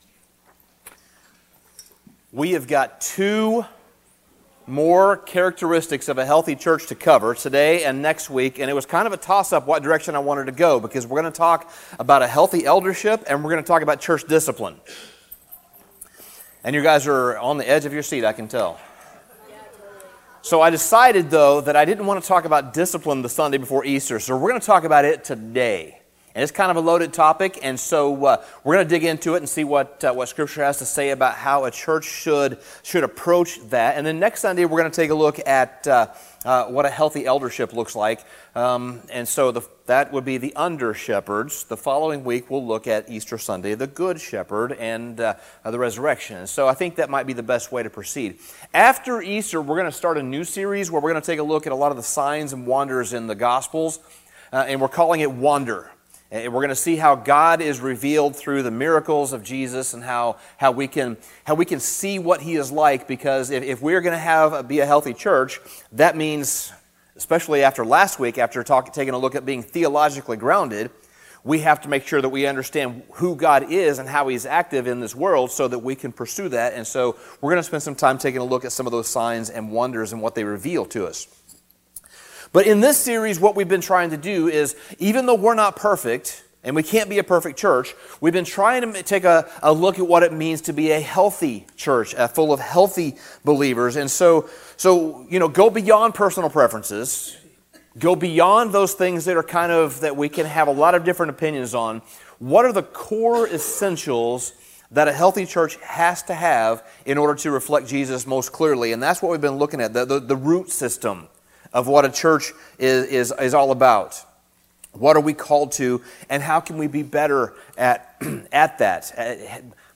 Sermon Summary One of the more intricate aspects of a healthy church is the concept of church discipline. Discipline is often neglected or simply ignored.